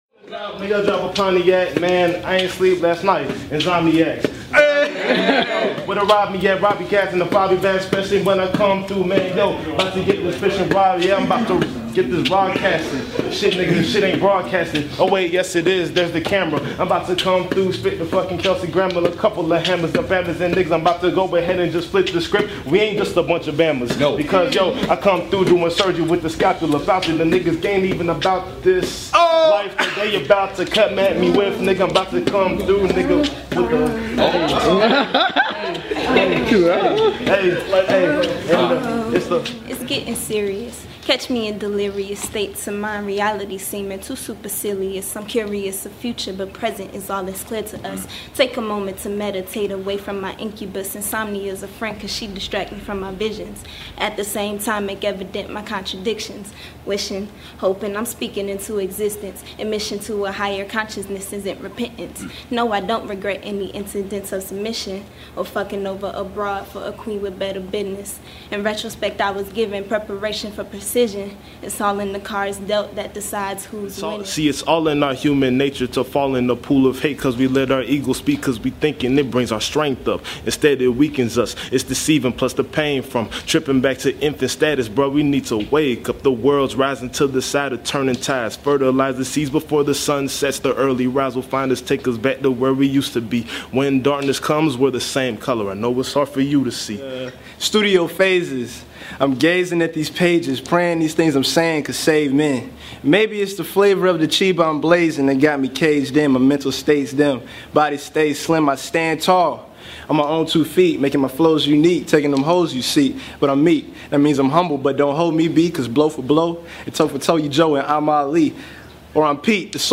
DEHH Indie Interviews